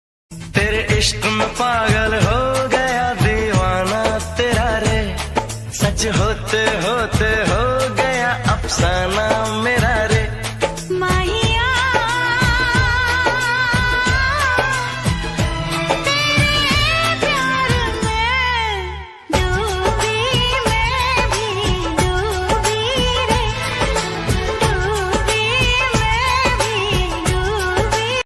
sad song ringtone